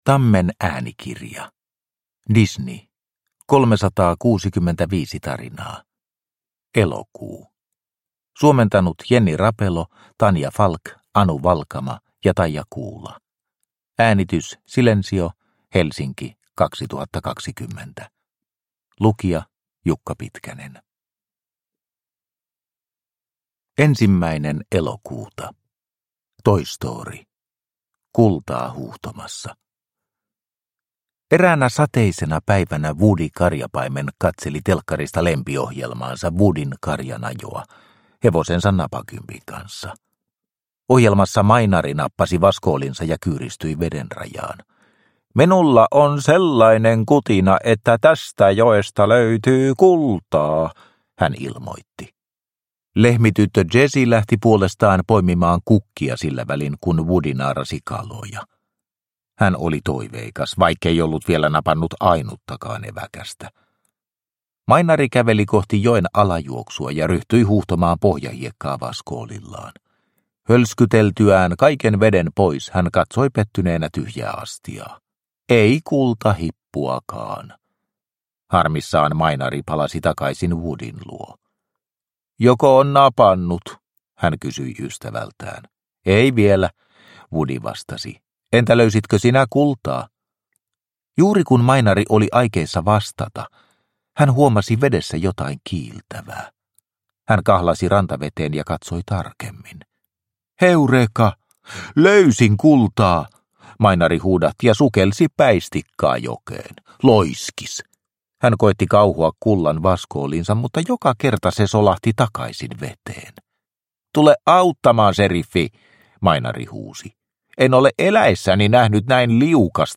Disney 365 tarinaa, Elokuu – Ljudbok – Laddas ner